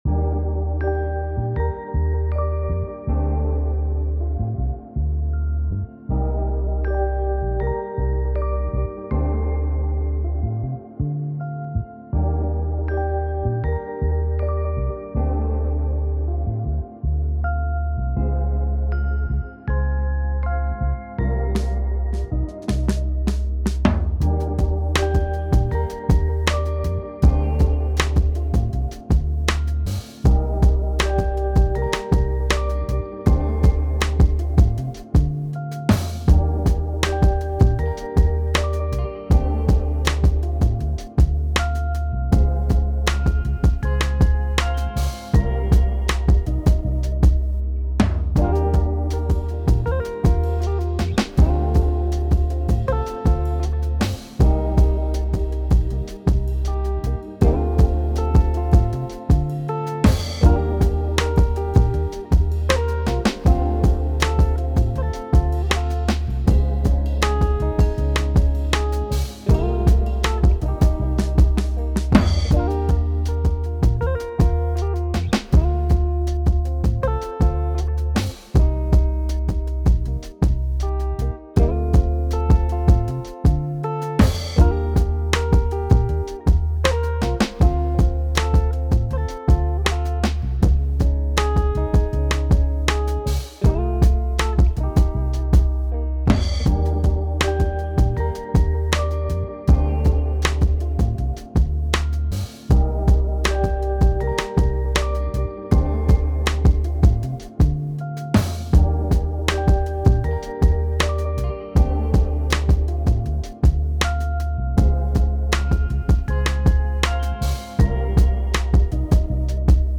R&B
Bmaj